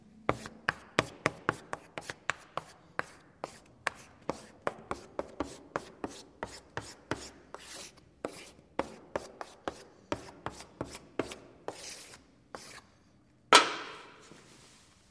Скрип мела по доске в аудитории студент решает задачу